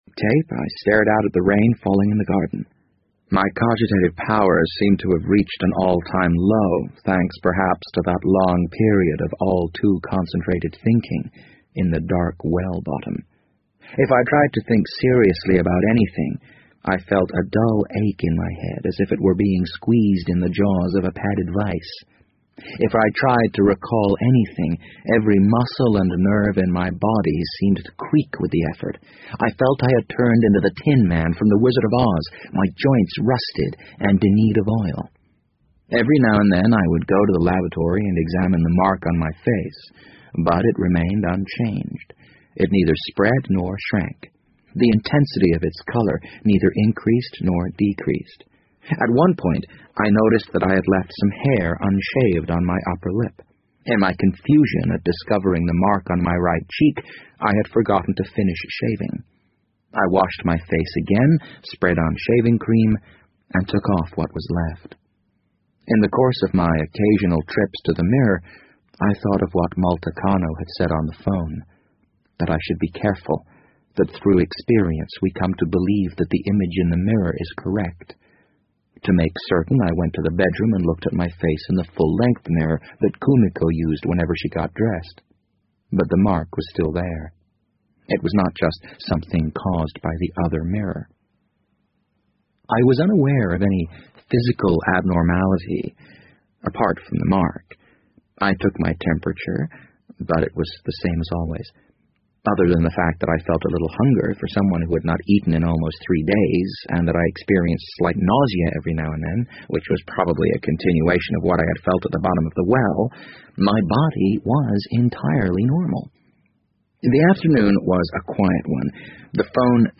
BBC英文广播剧在线听 The Wind Up Bird 008 - 2 听力文件下载—在线英语听力室